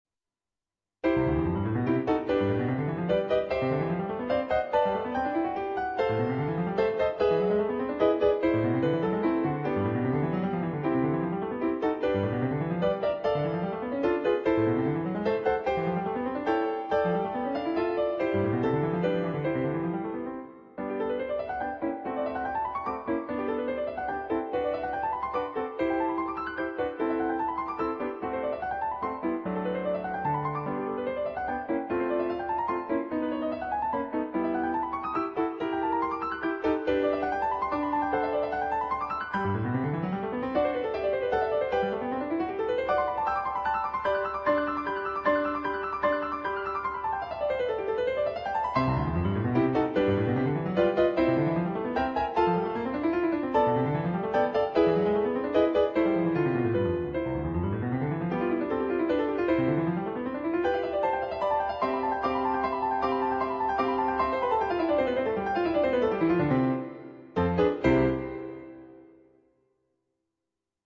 5. in C Major (1'10")
on Yamaha digital pianos.